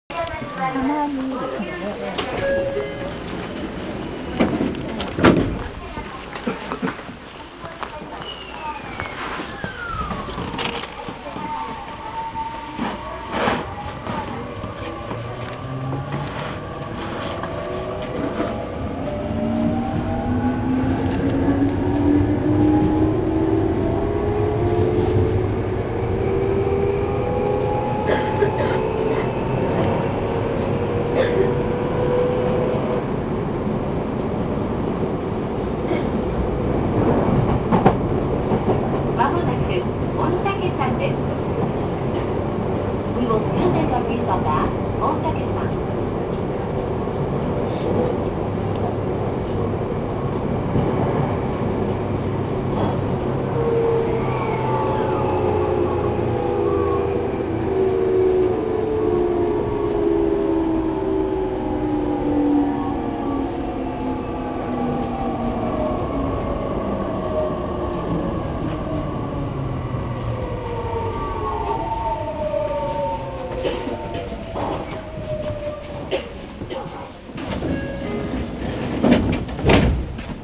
・1000系1500番台走行音
【池上線】久が原〜御嶽山（1分20秒：438KB）
編成組み換えの際にVVVFもIGBTに交換されましたが、東芝製のものを採用しており、あまり聞く事が出来ないモーター音を聞く事が出来ます。ドアチャイムは特に変更されていません。